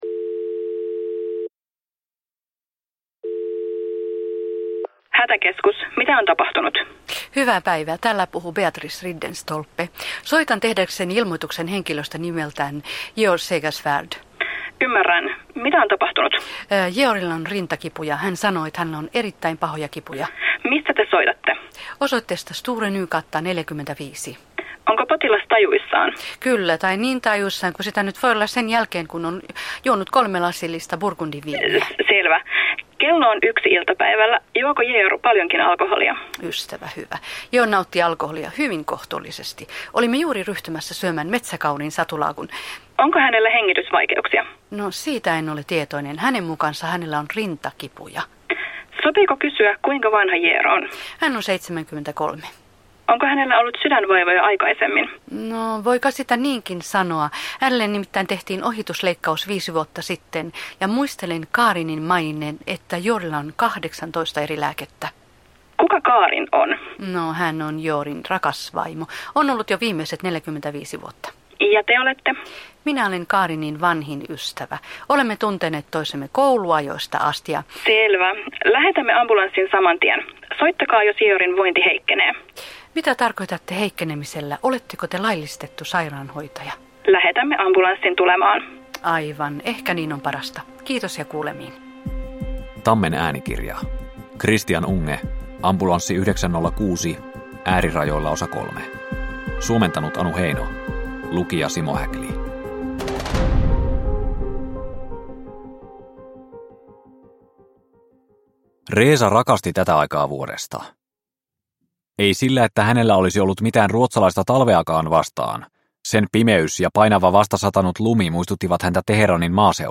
Ambulanssi 906 Osa 3 – Ljudbok – Laddas ner